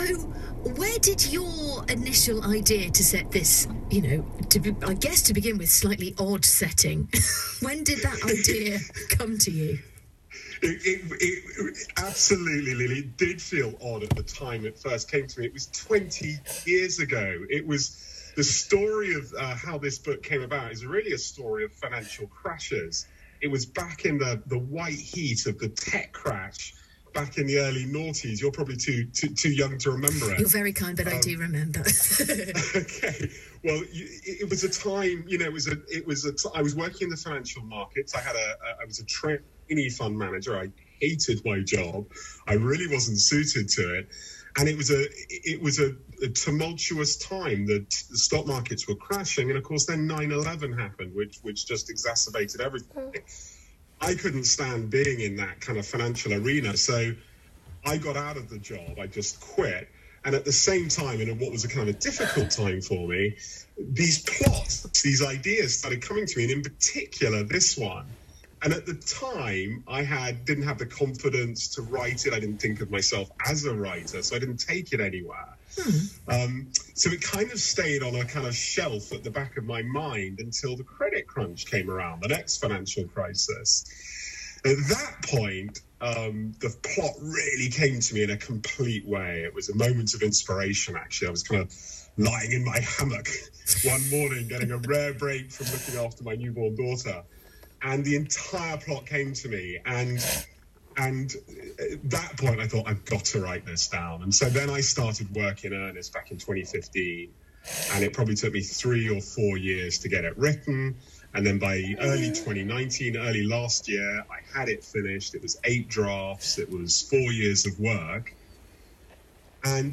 The sound quality is decent, given the circ's. The first question of the interview is missing, but otherwise it's all there.